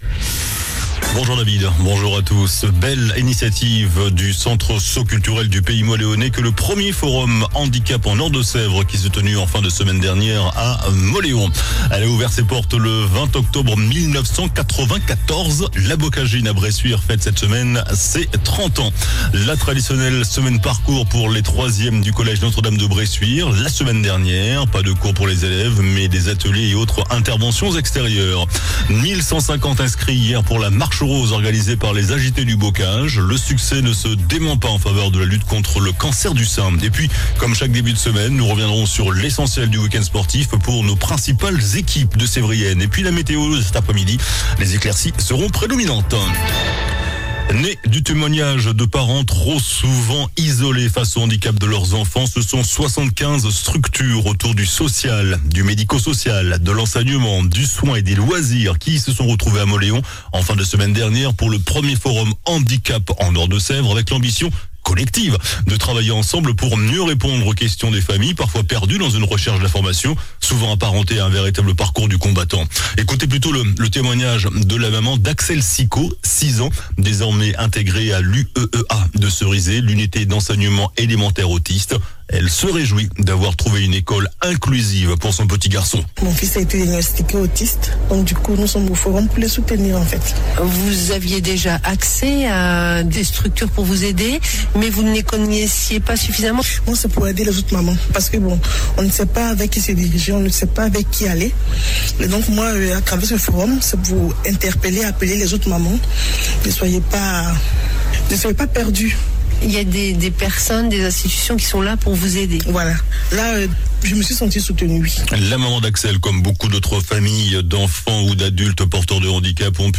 JOURNAL DU LUNDI 21 OCTOBRE ( MIDI )